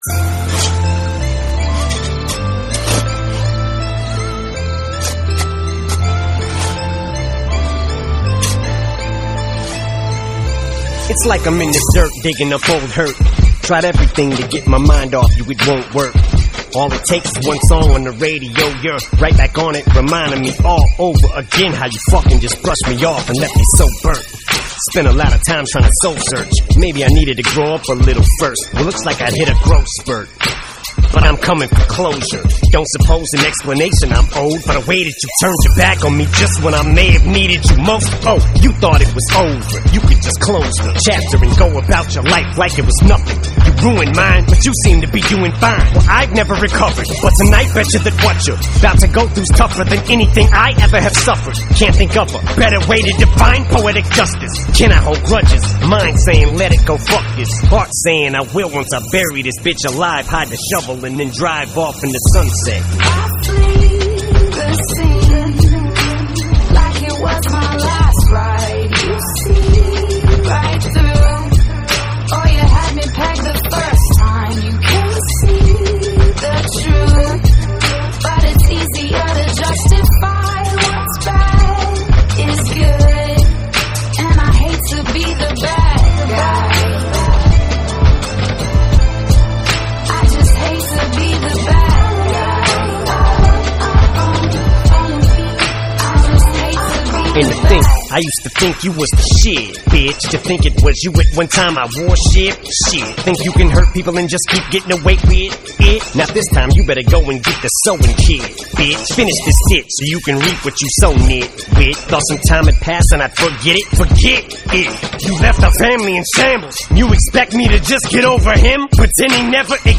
Hip-Hop, Hardcore Hip-Hop